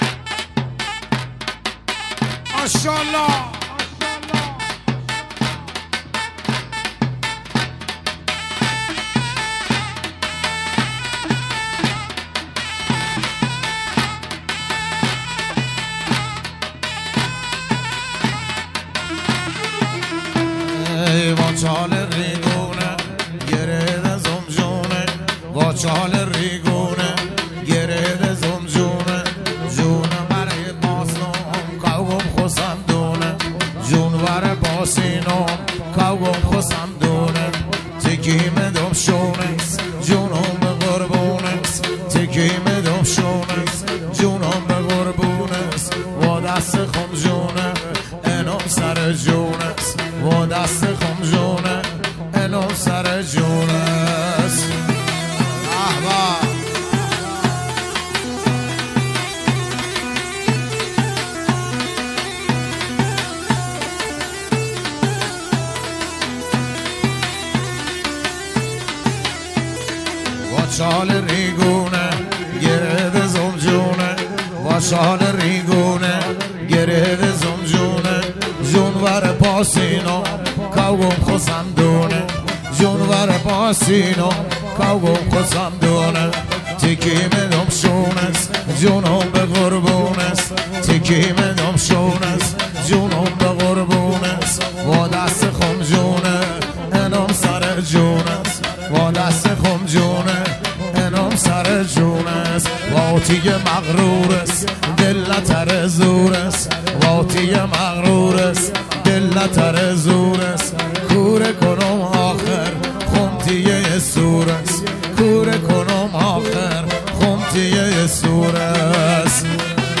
شاد رقص محلی لری عروسی